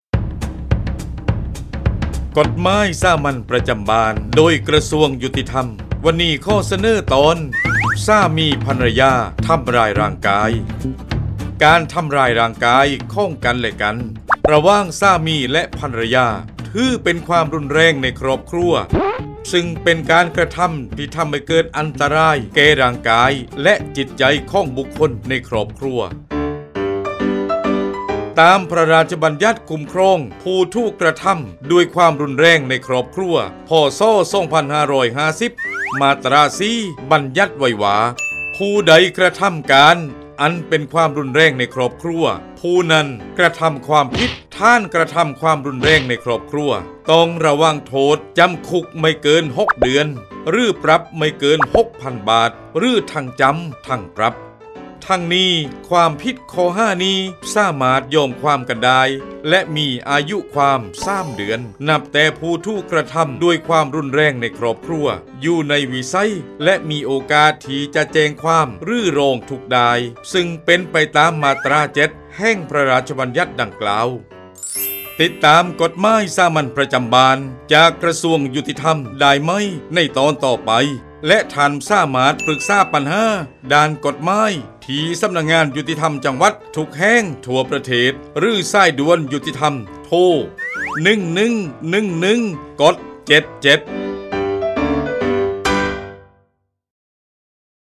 กฎหมายสามัญประจำบ้าน ฉบับภาษาท้องถิ่น ภาคใต้ ตอนสามี-ภรรยา ทำร้ายร่างกาย
ลักษณะของสื่อ :   บรรยาย, คลิปเสียง